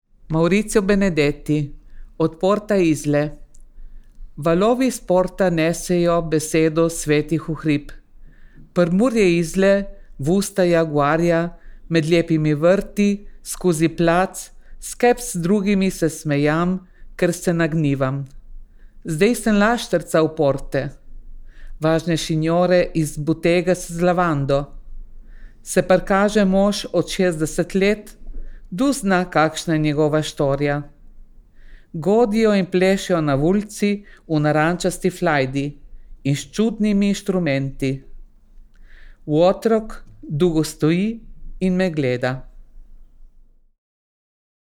ISTRSKO NAREČJE  (PRIMORSKA NAREČNA SKUPINA)
Avdio posnetek istrskega narečja, Obalni dom upokojencev Koper: